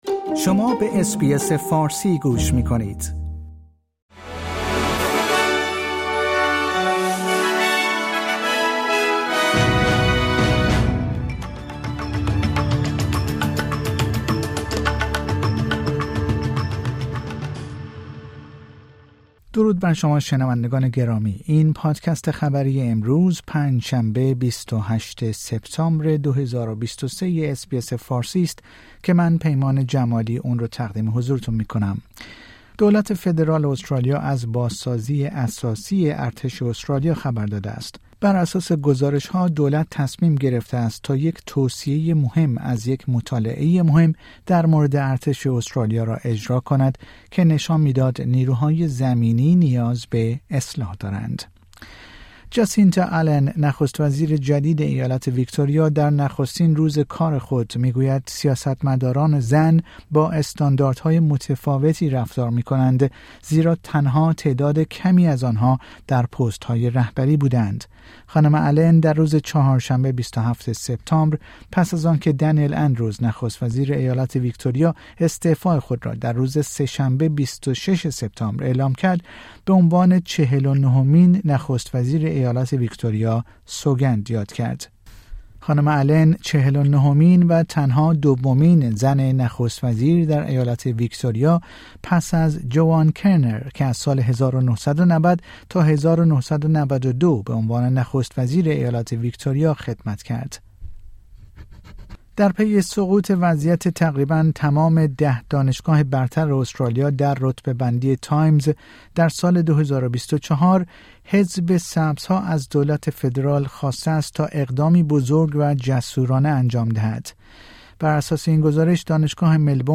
در این پادکست خبری مهمترین اخبار استرالیا و جهان در روز پنج شنبه ۲۸ سپتامبر ۲۰۲۳ ارائه شده است.